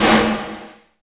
50 VERB SN-R.wav